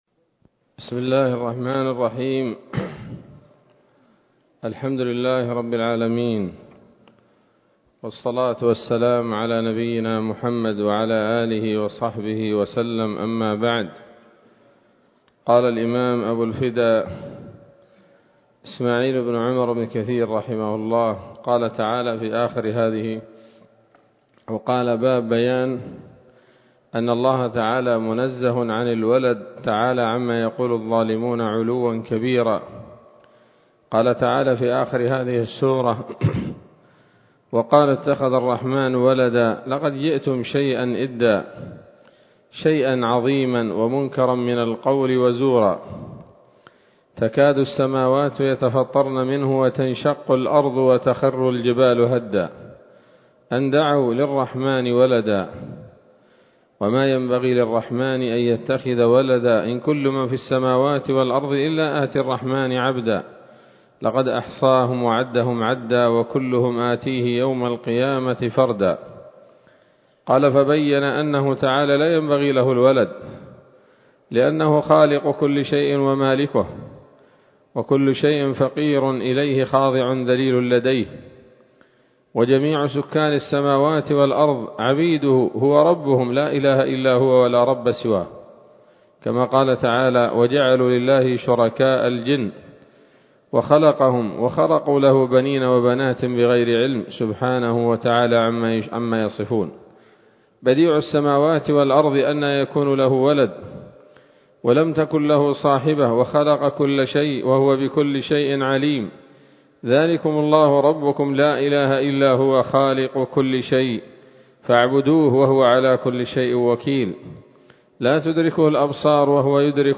‌‌الدرس الرابع والأربعون بعد المائة من قصص الأنبياء لابن كثير رحمه الله تعالى